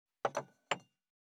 259,会食の音,食事の音,カチャン,コトン,効果音,環境音,BGM,カタン,チン,コテン,コン,カチャ,チリ,チャリン,カラン,トン,シャリン,
コップ